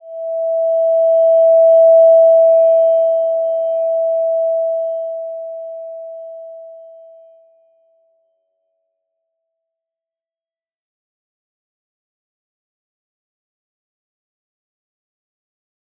Slow-Distant-Chime-E5-mf.wav